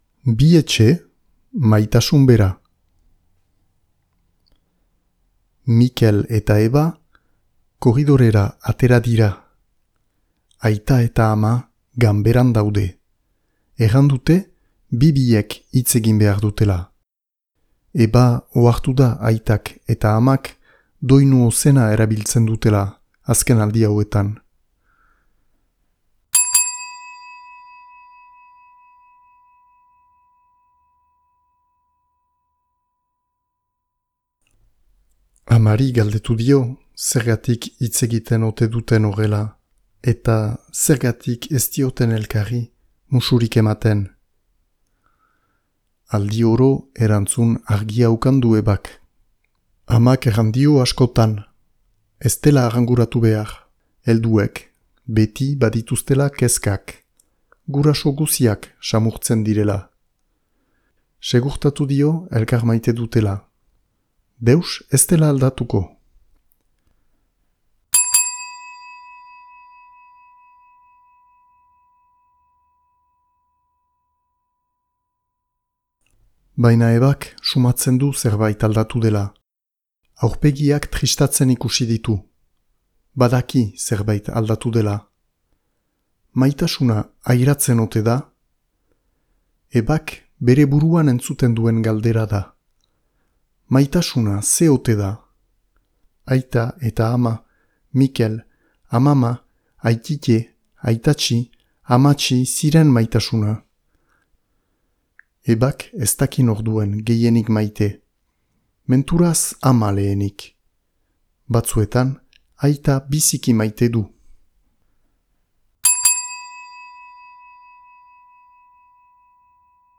Bi etxe, maitasun bera - Batuaz - ipuina entzungai